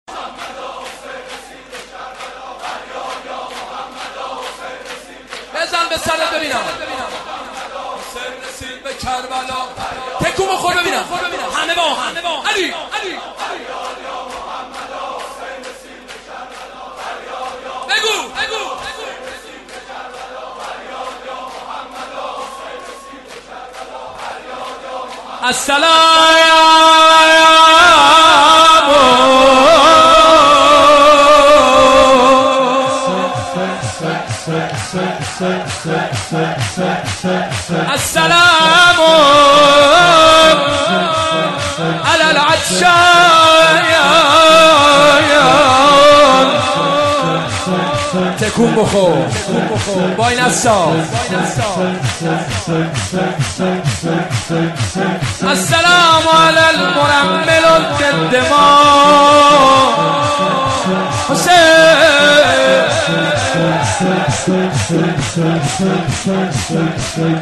شب دوم محرم
هروله